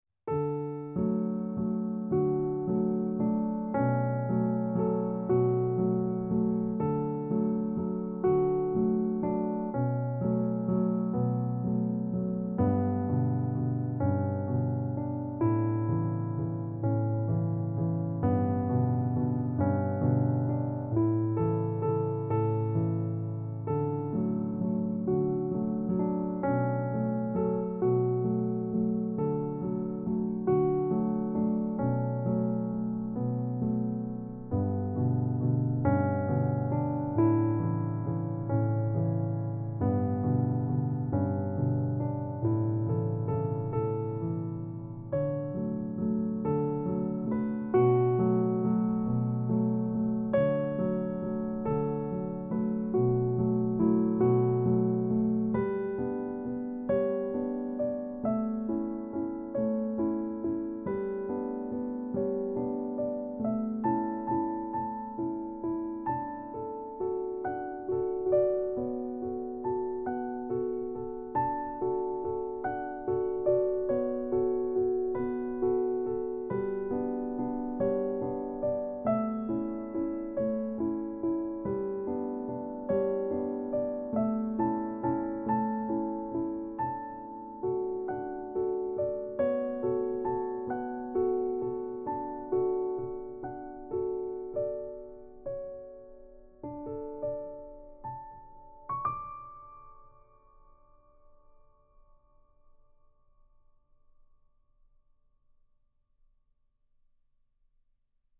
ある方にピアノを褒められたので、調子乗って録音したのをいくつかアップします！